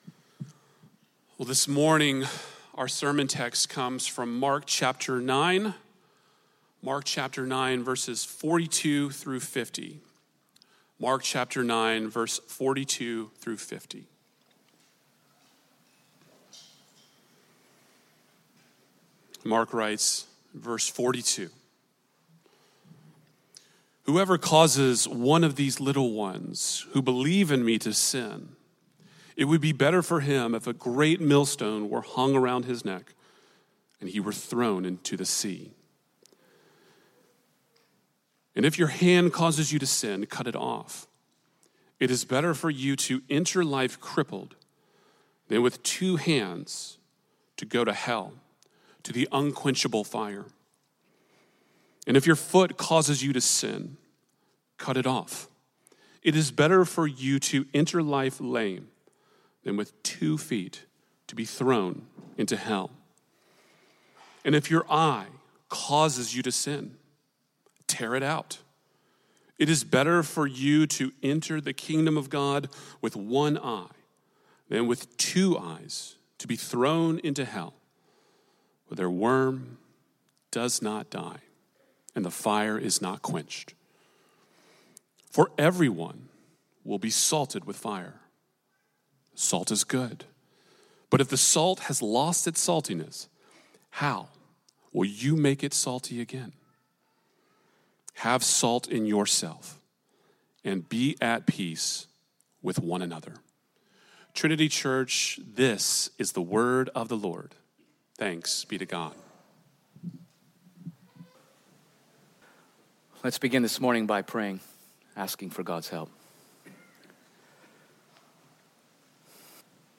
The text of our sermon displays the side of Jesus preaching about the seriousness of sin related both to causing other Christians to stumble, and sin that jeopardizes our relationship with Jesus. Jesus warns that hell is a reality for the one who embraces sin.